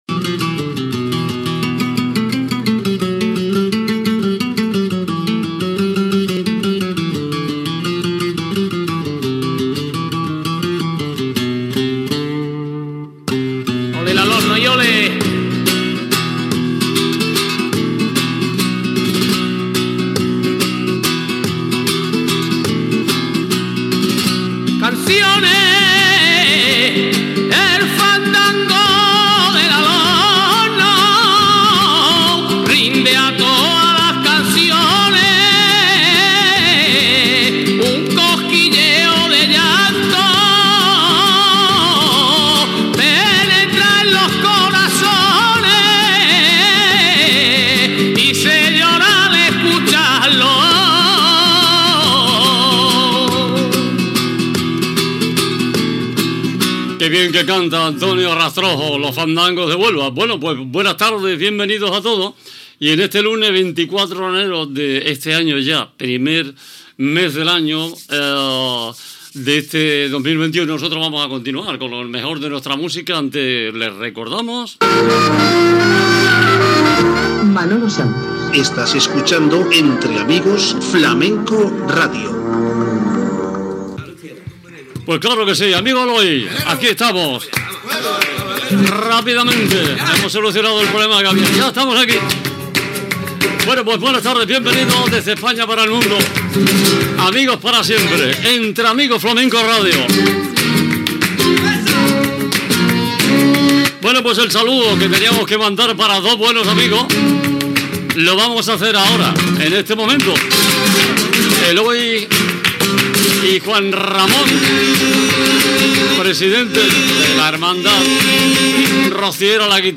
Presentació, data, indicatiu del programa, salutació a dos oïdors, tema musical
Musical